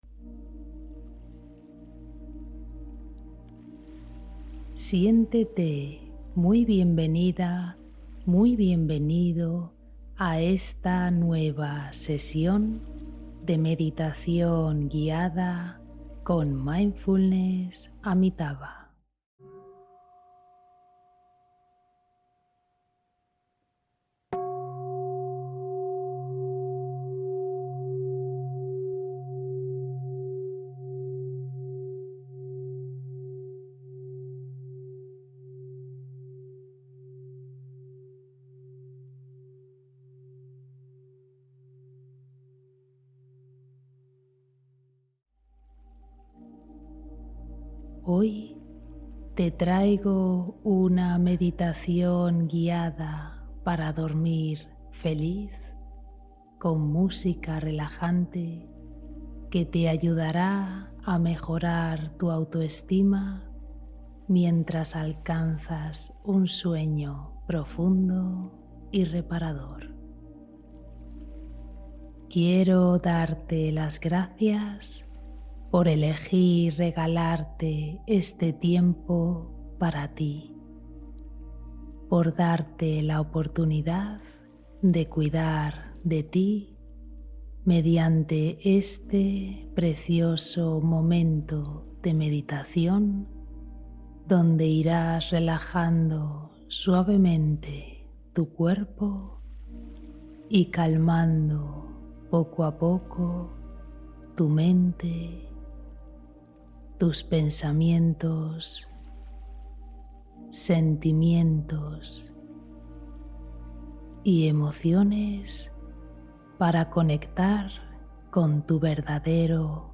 Descanso zen: meditación para favorecer un sueño profundo